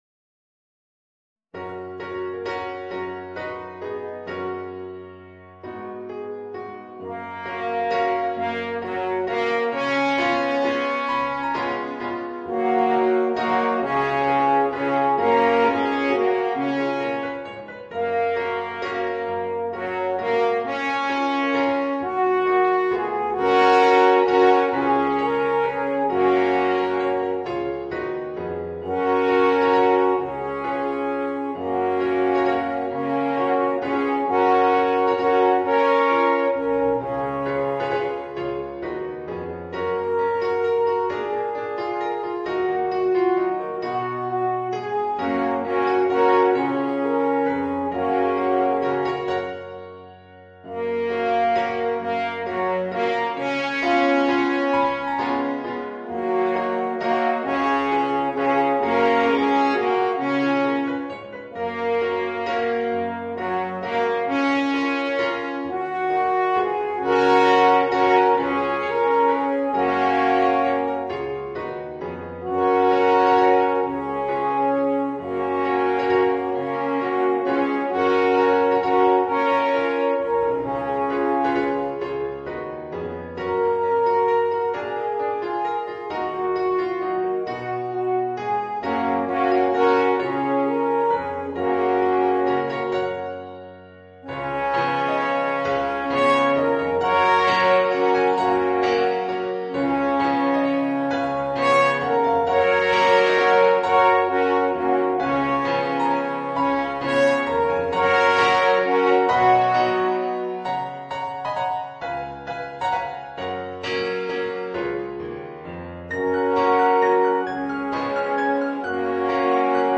Voicing: Alphorn and Piano